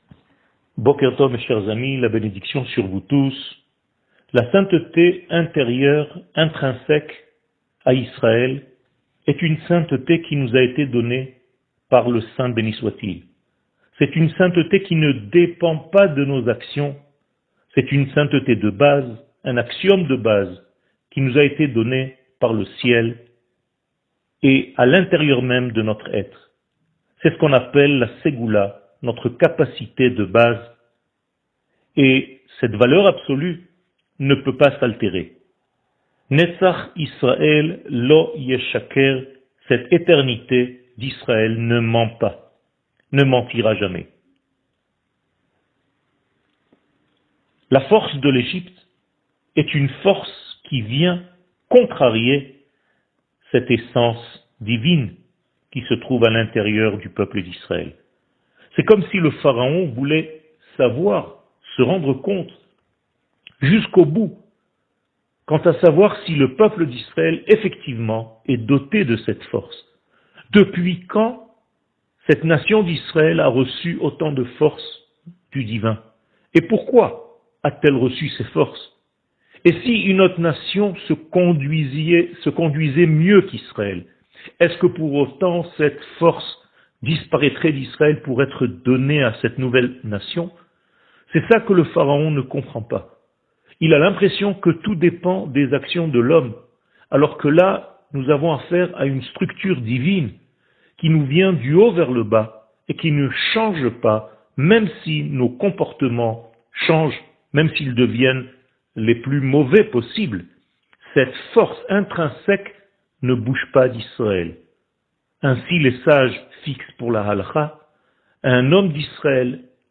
שיעור מ 04 ינואר 2022